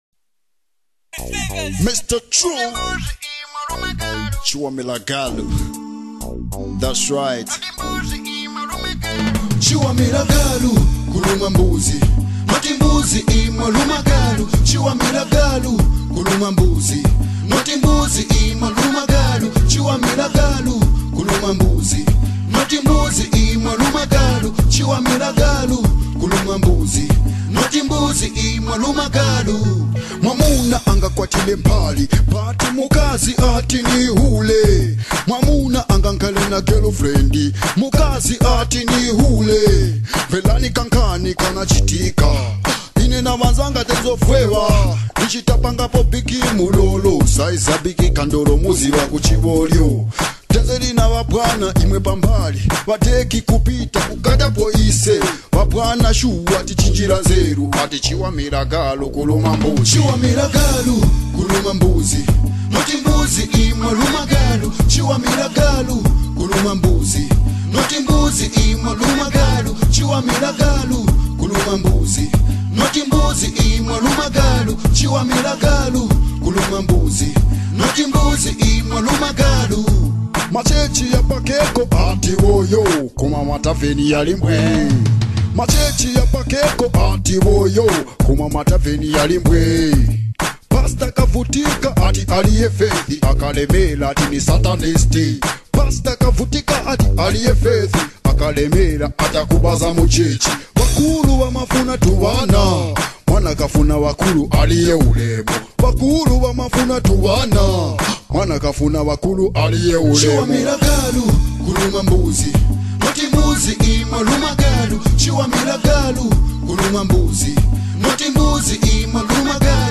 Zambian song
particularly in genres like Kalindula and Zambian pop.
catchy rhythms and engaging lyrics